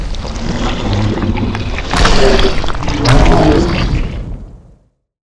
Index of /client_files/Data/sound/monster/dx1/